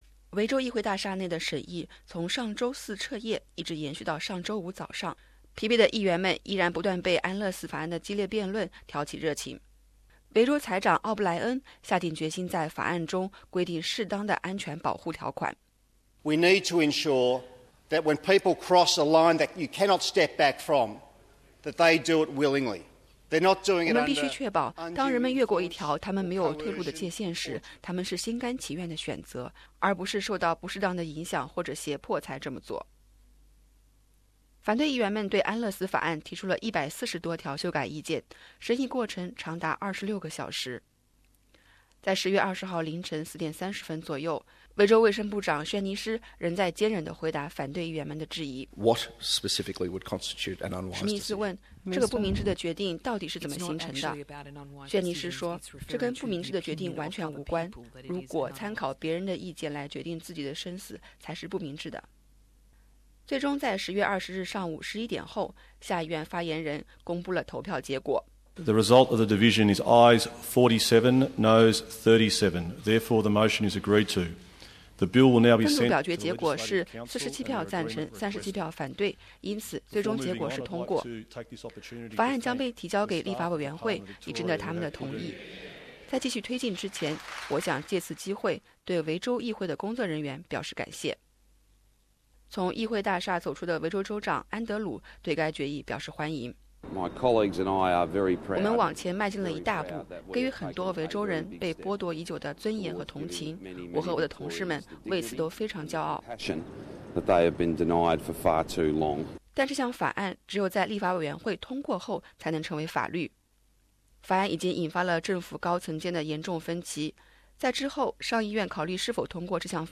维州：下议院通过安乐死法案，距合法化更近一步 02:50 Source: AAP SBS 普通话电台 View Podcast Series Follow and Subscribe Apple Podcasts YouTube Spotify Download (1.3MB) Download the SBS Audio app Available on iOS and Android 10月20日上午，在经过漫长的彻夜审议后，安乐死法案在维州下议院通过，并将在两周内被提交给上议院。